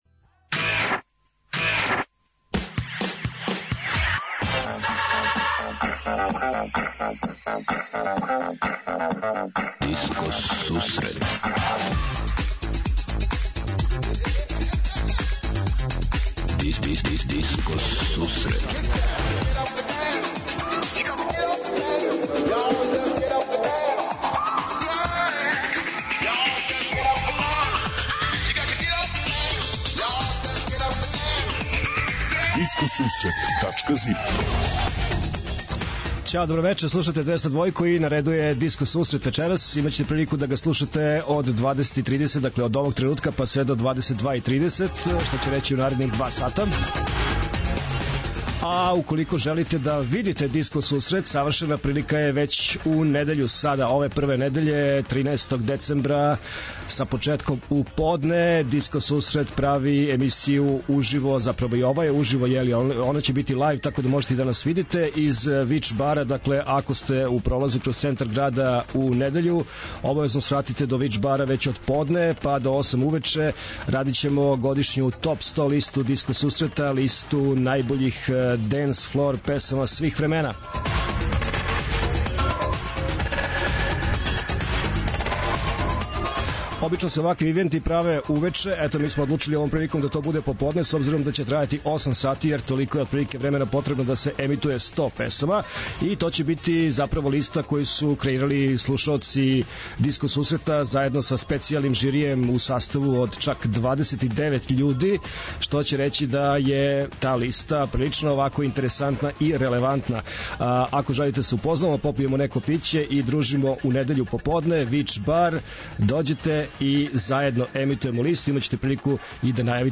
20:30 Диско Сусрет Топ 40 - Топ листа 40 актуелних синглова, пажљиво одабраних за оне који воле диско музику. 21:30 Italo Play - Колекција синглова, које препоручује тим italo-disco експерата.
преузми : 28.47 MB Discoteca+ Autor: Београд 202 Discoteca+ је емисија посвећена најновијој и оригиналној диско музици у широком смислу, укључујући све стилске утицаје других музичких праваца - фанк, соул, РнБ, итало-диско, денс, поп.